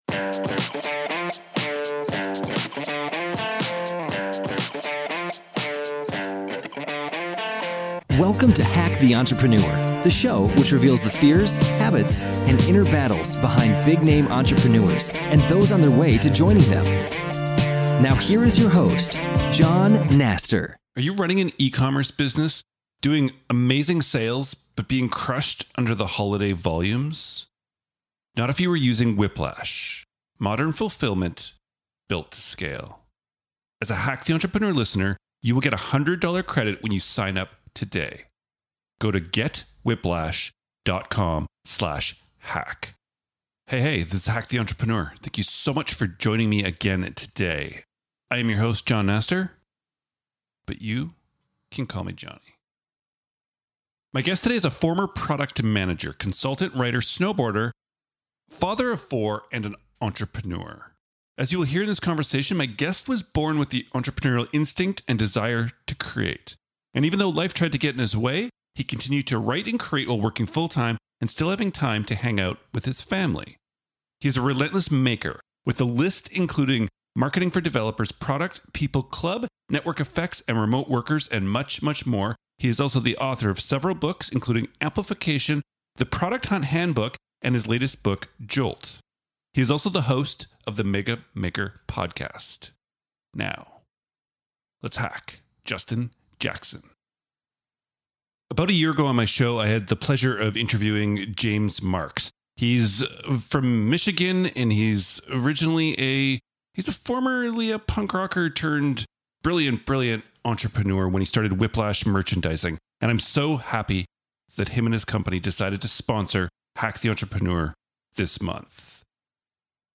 As you will hear in this conversation, my guest was born with the entrepreneurial instinct and desire to create.